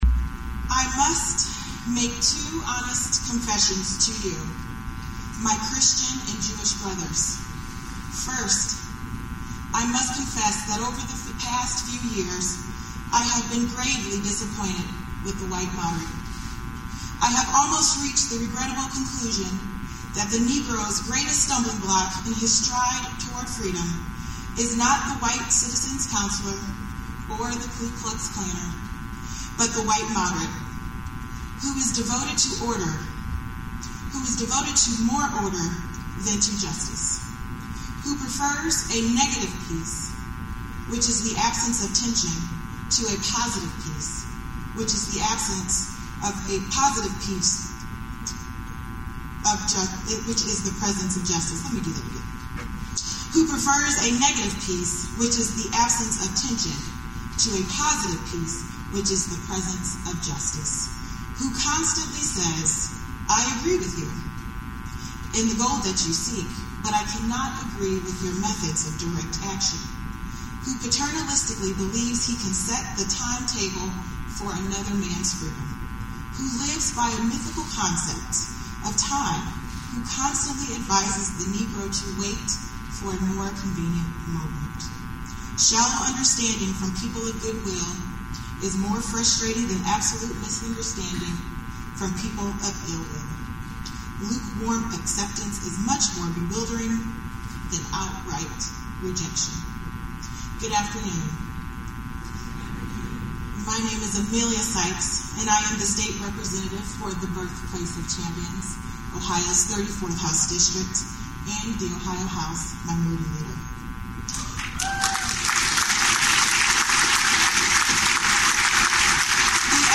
Akron legislator Emilia Sykes recalled Dr. King’s work as she delivered a lecture Sunday at the Akron Library in his honor.
sykes_speech.mp3